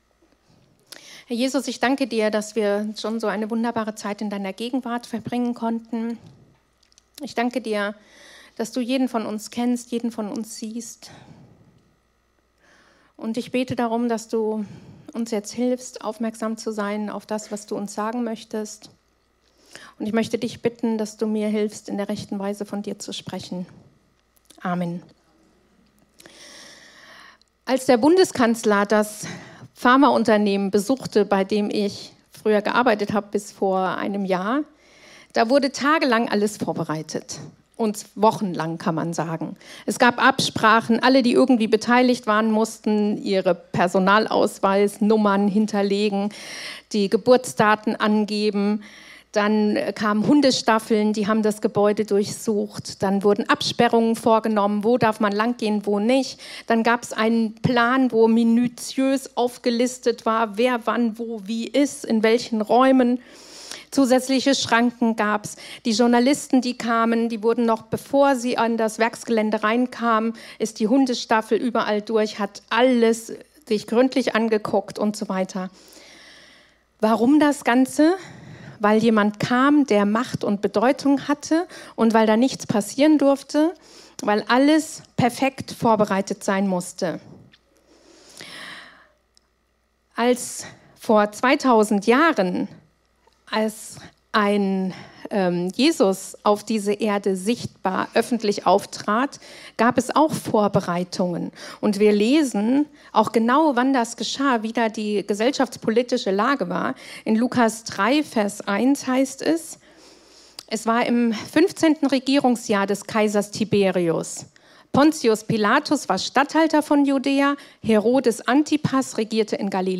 Predigten aus der freien Christengemeinde Die Brücke in Bad Kreuznach.